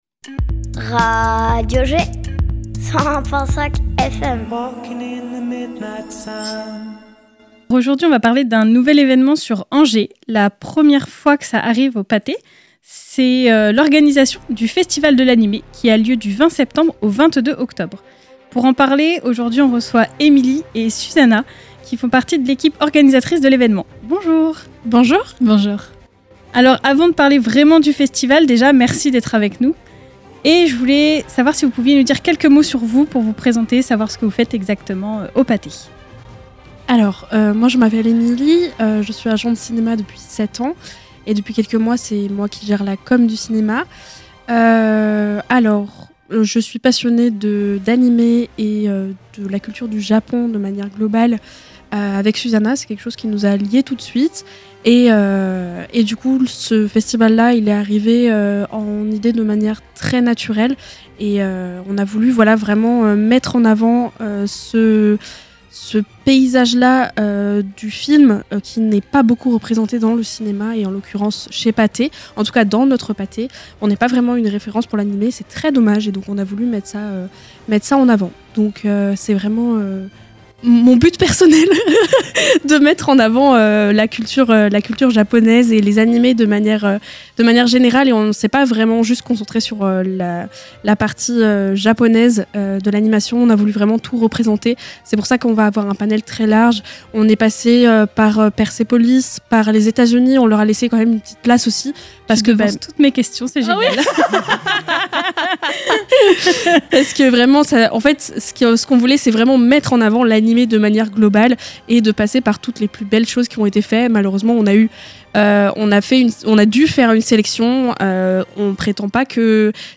interviewpatheangers.mp3